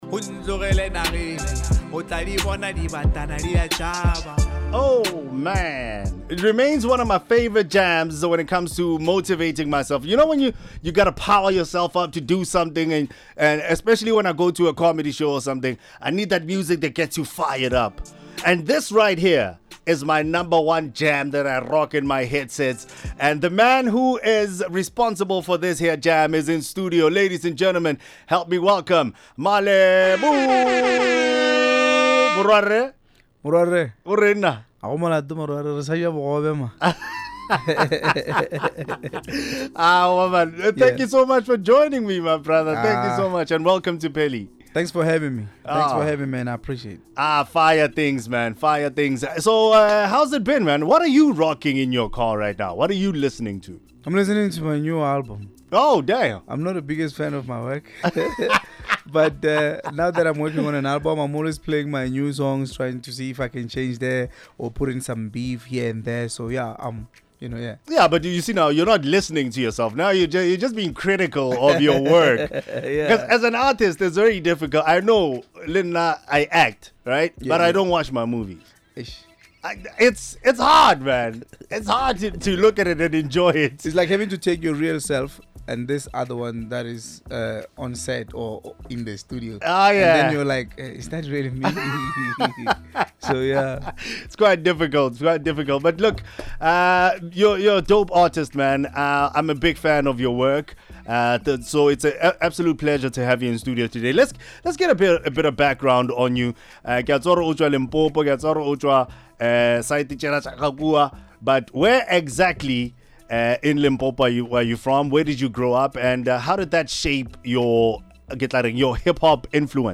If you missed any part of the interview, don’t worry – we’ll have the full podcast available on our website soon.